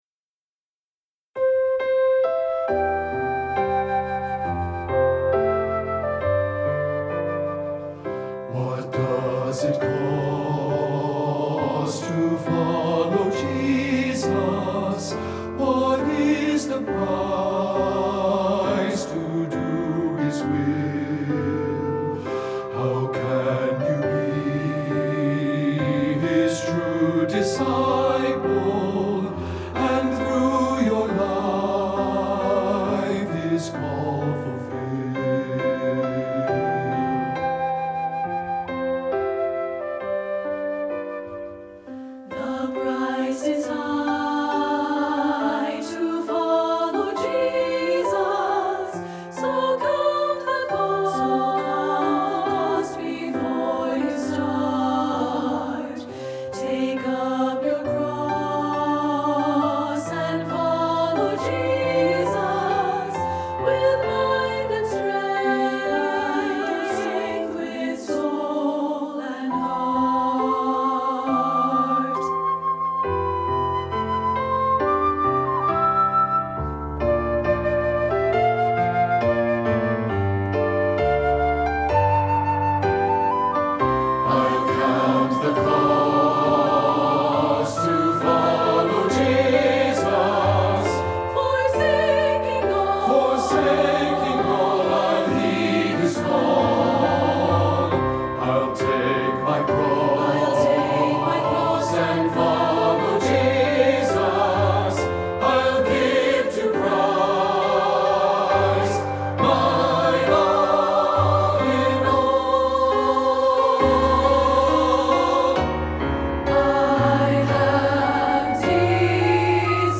Voicing: SA(T)B with Optional Flute or C-Instrument